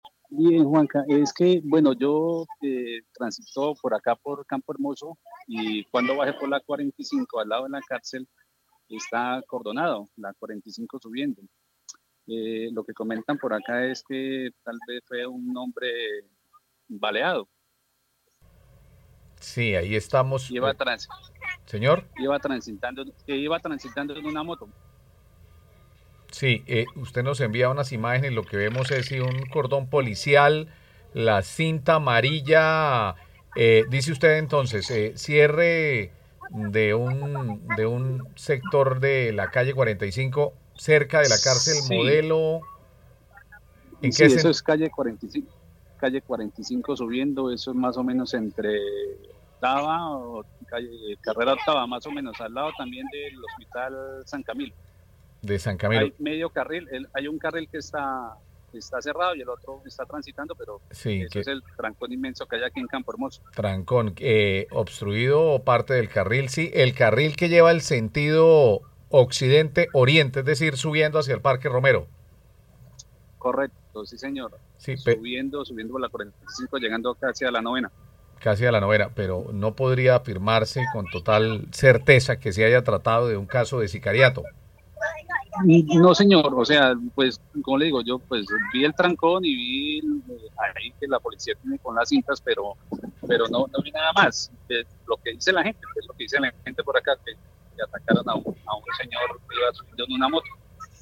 Testigo de balacera cuenta qué ocurrió cerca de la cárcel Modelo de Bucaramanga